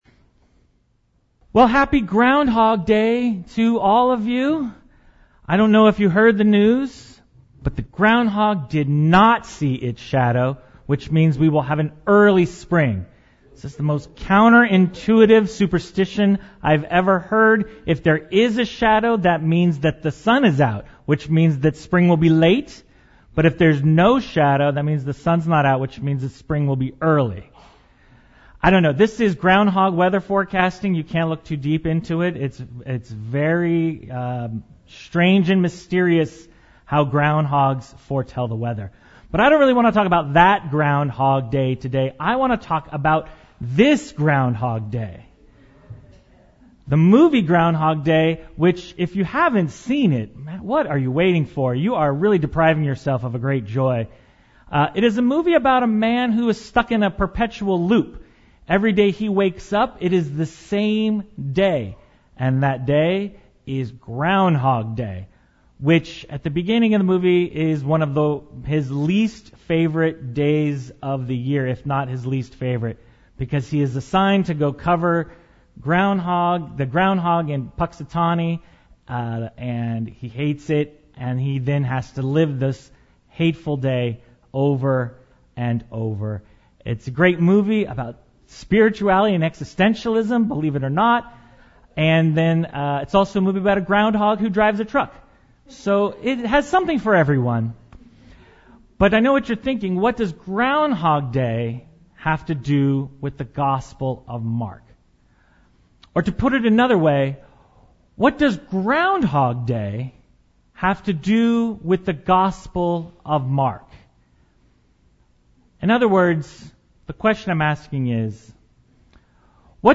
February 4, 2024 Sermon